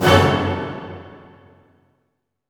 Index of /90_sSampleCDs/Roland L-CD702/VOL-1/HIT_Dynamic Orch/HIT_Orch Hit min
HIT ORCHM08L.wav